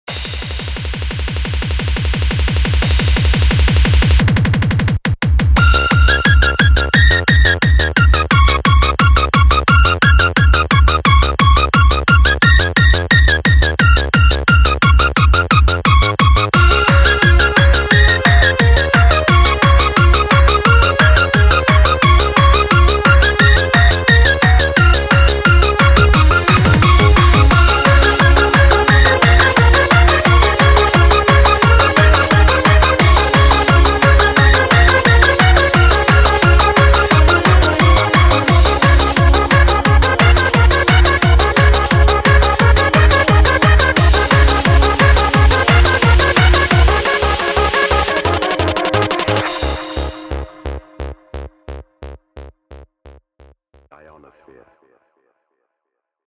Nice & bouncy, just the way i like my Hardcore.